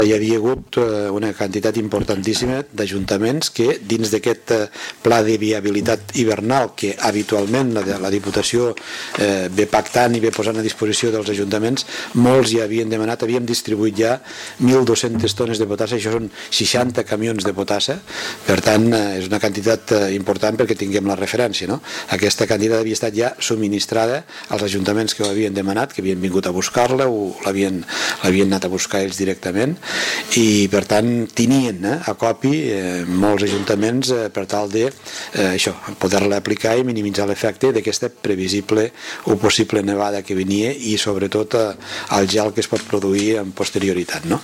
El president de la Diputació de Lleida ha comparegut en roda de premsa acompanyat dels responsables de carreteres per tal de fer una valoració global de la situació. Joan Reñé ha explicat que des de les 2 de la matinada els equips de la Diputació han estat treballant repartint sal i enretirant la neu de les carreteres amb màquines i camions llevaneus.